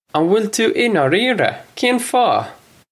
Pronunciation for how to say
Un will too in-aw-ree-ruh? Kayn faw?
This is an approximate phonetic pronunciation of the phrase.